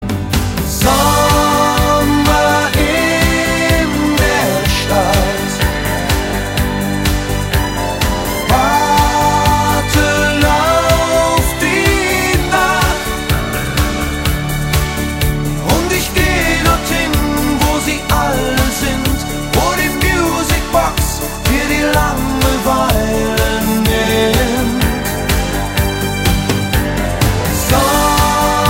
Gattung: Moderner Einzeltitel mit Gesang
Besetzung: Blasorchester
Ein Erfolgsschlager
für Blasmusik (mit Gesang)
Tonart: F-Dur